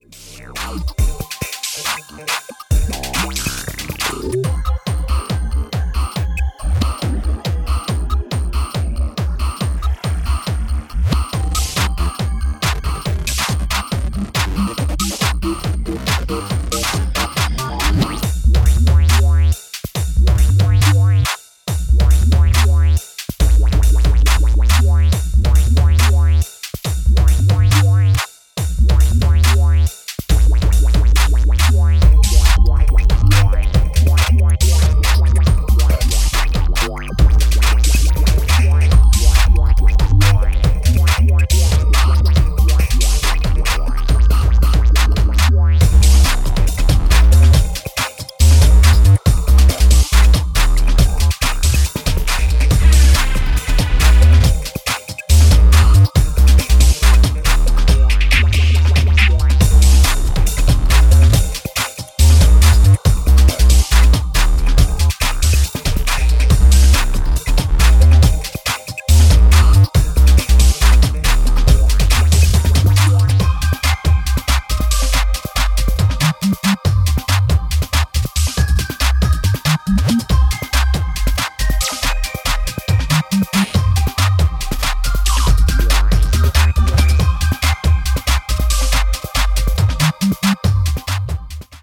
original acid music for classy dance floors.
Electro